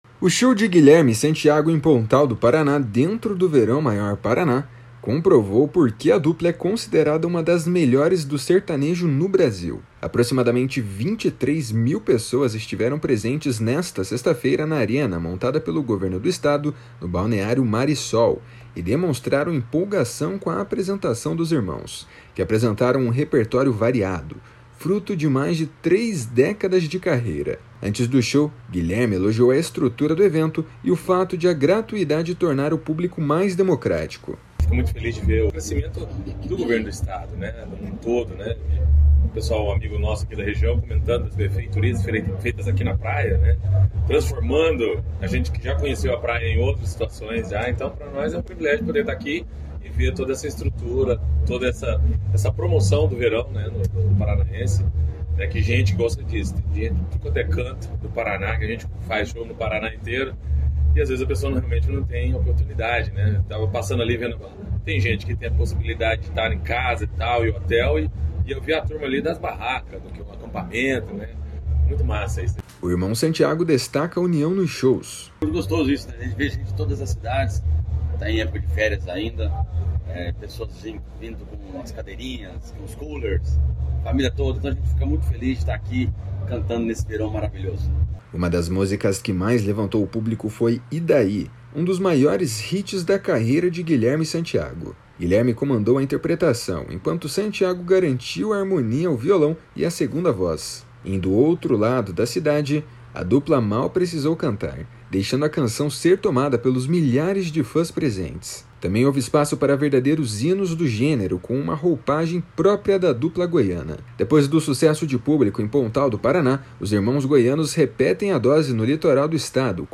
Guilherme comandou a interpretação, enquanto Santiago garantiu a harmonia ao violão e a segunda voz. Em “Do Outro Lado da Cidade”, a dupla mal precisou cantar, deixando a canção ser tomada pelos milhares de fãs presentes.